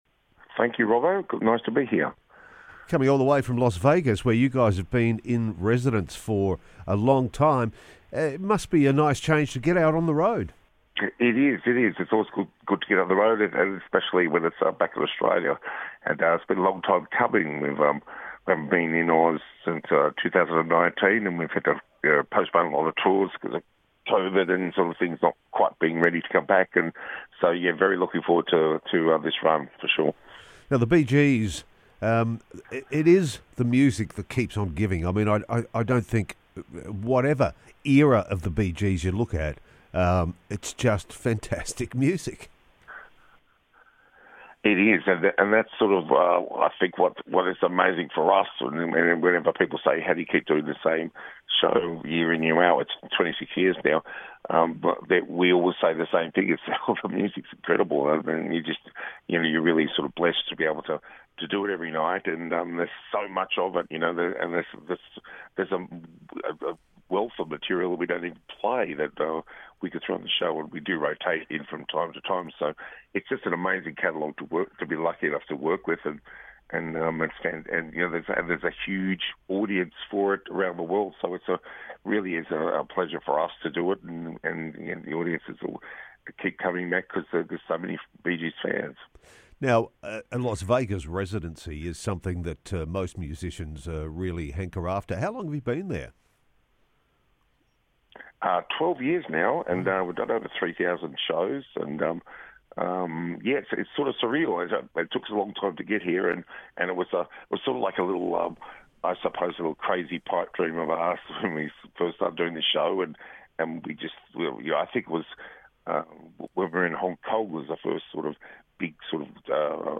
for a chat on The Wake Up Call...